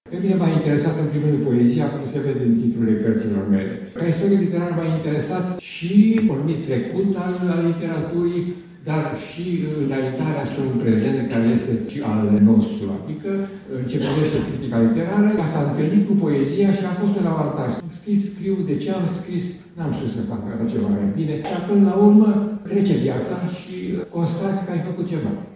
Aseară, la Botoșani, au fost decernate Premiile Culturii Naționale, în cadrul unei Gale care a încheiat prima zi din seria Zilelor Eminescu.
Ceremonia s-a desfășurat în sala Teatrului „Mihai Eminescu” din Botoșani și a fost organizată de Memorialul Ipotești – Centrul Național de Studii „Mihai Eminescu”, cu sprijinul Ministerului Afacerilor Externe.